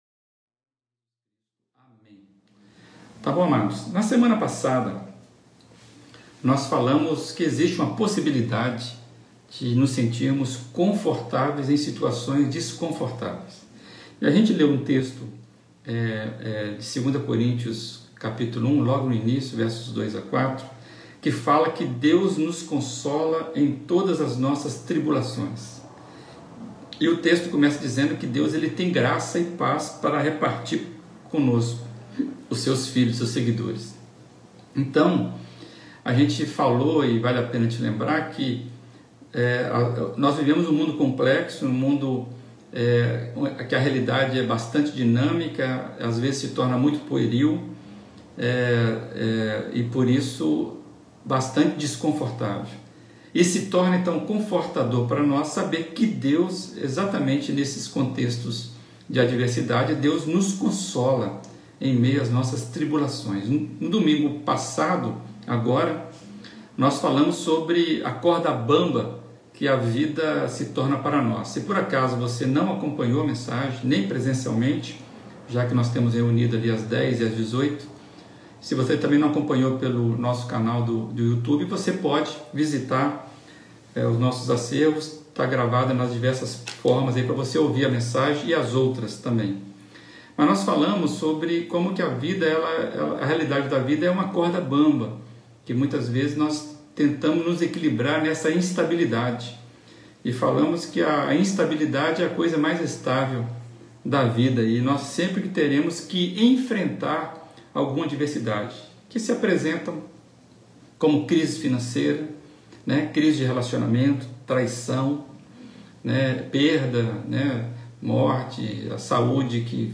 Mensagem
Primeira Igreja Batista de Brusque